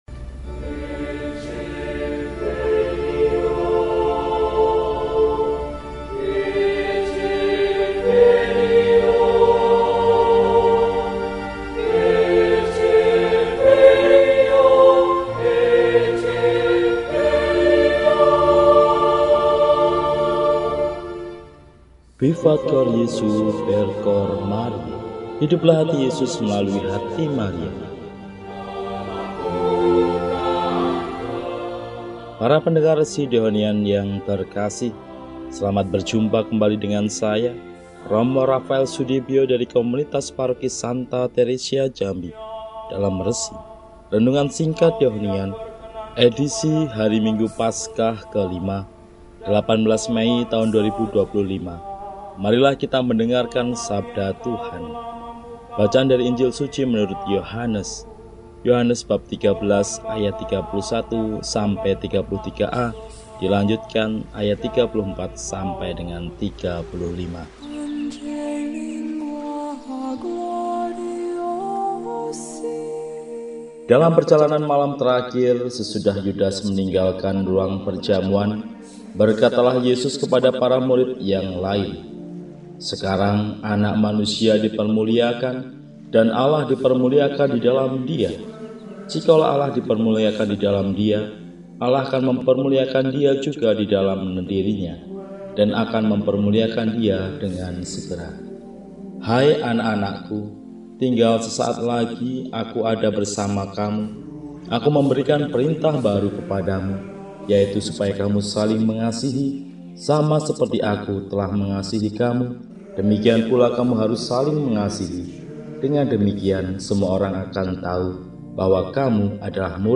Minggu, 18 Mei 2025 – Hari Minggu Paskah V – RESI (Renungan Singkat) DEHONIAN